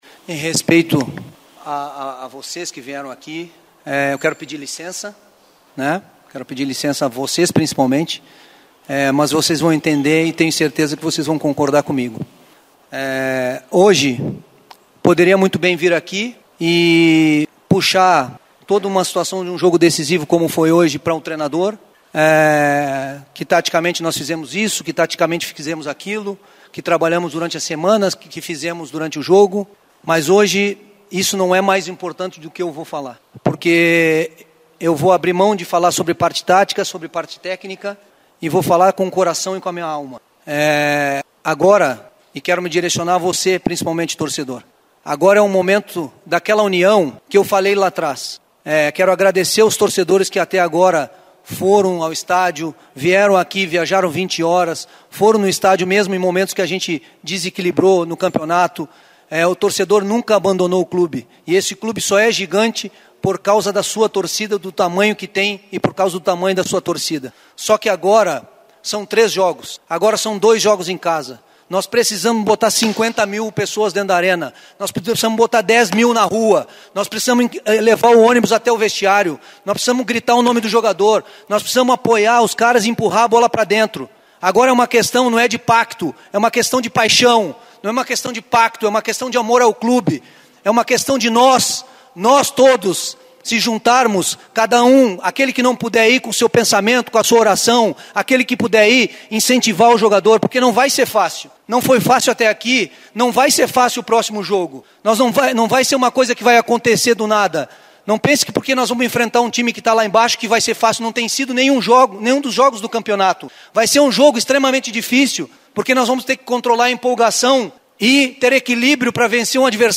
Depois da vitória sobre o Goiás, por 1 a 0, sábado passado (1) em Goiânia, Odair Hellmann chamou a torcida do Athletico para lotar a Arena da Baixada nos dois últimos jogos do time como mandante na Série B do Campeonato Brasileiro: diante do Volta Redonda, no próximo sábado (8), às 18h30; e contra o América-MG, no dia 23, às 16h30. O treinador afirmou que o rubro-negro vai conseguir o acesso para a Série A e, na empolgação, falou que é preciso que haja uma multidão até do lado de fora do estádio.